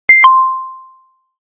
SE（起動音）
古いパソコンの起動音。ピコン。